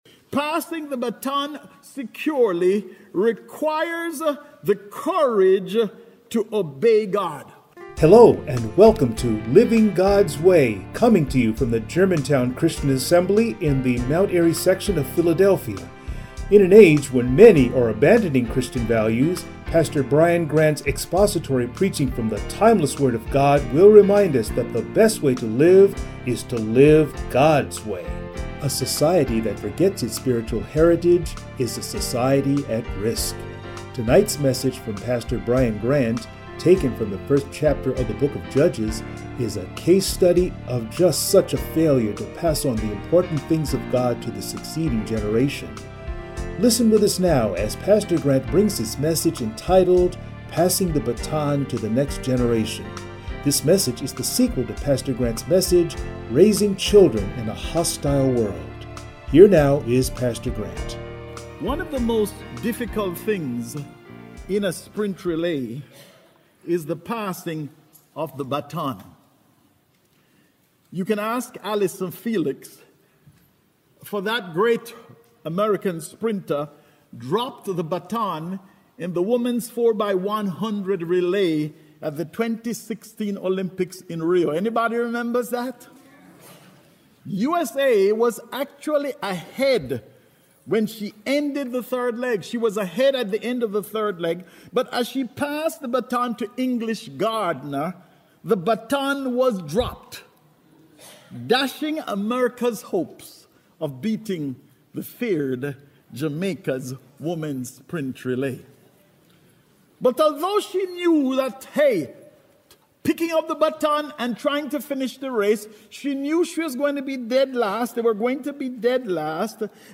Judges 1:1-19 Service Type: Sunday Morning A society that forgets its spiritual heritage is a society at risk.